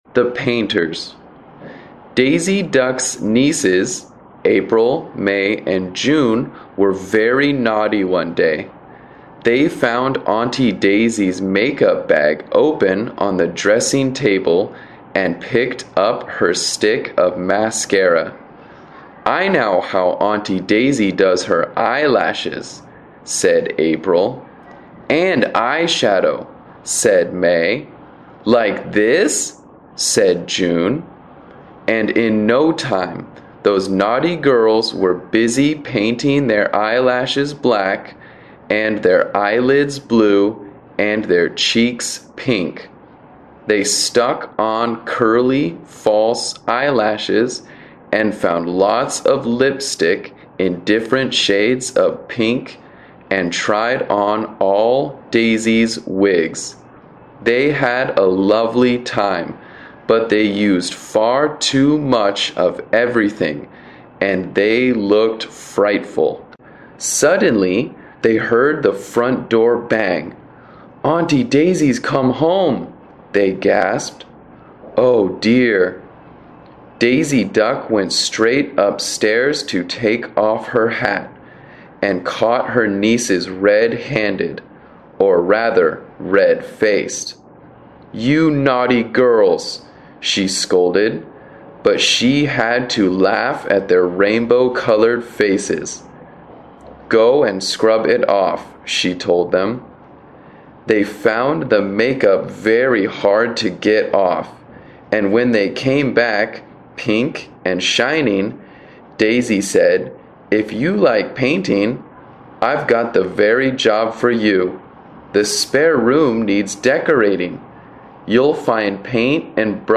桃園市朗讀第三篇The Painters.mp3